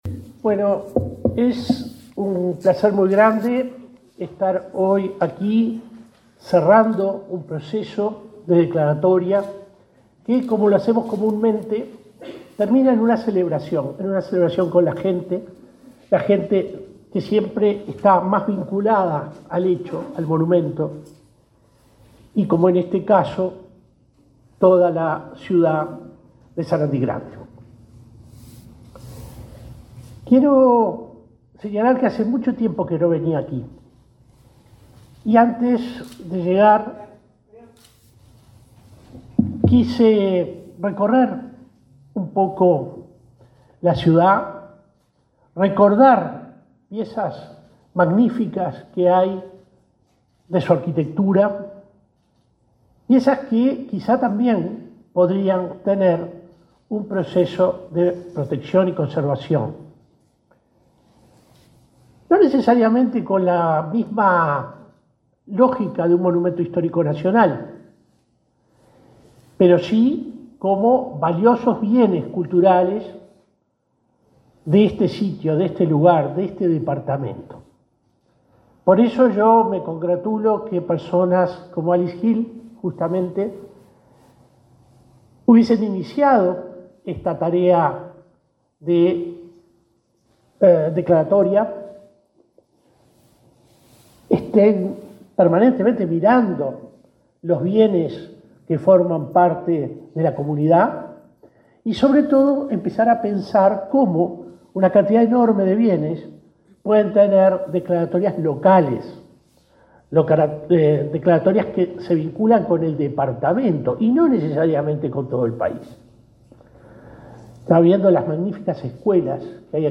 El presidente de la Comisión del Patrimonio Cultural de la Nación, William Rey, y el ministro Pablo da Silveira participaron, este martes 31 en